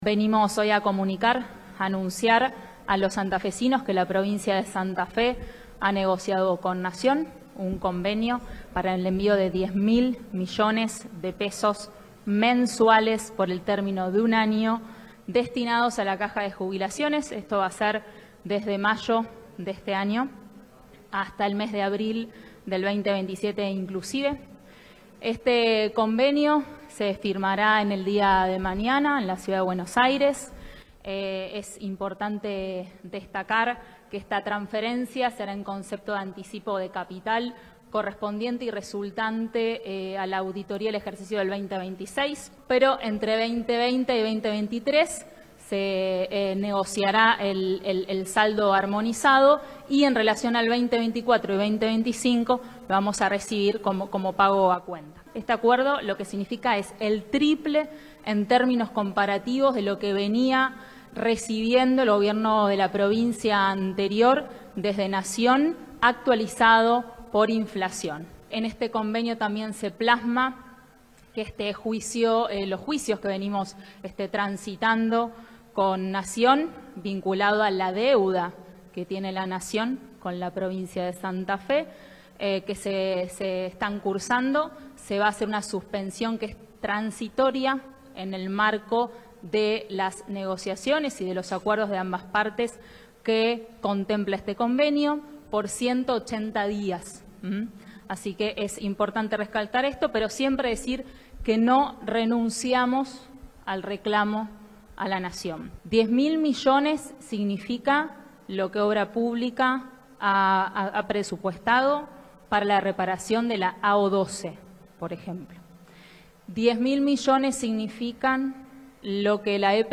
“El convenio lo firmará el gobernador Maximiliano Pullaro junto a autoridades de Anses este martes en la ciudad autónoma de Buenos Aires”, anunciaron la secretaria de Gestión Institucional, Virginia Coudannes, y el secretario de Seguridad Social, Jorge Boasso, en conferencia de prensa en la Casa de Gobierno de la ciudad capital.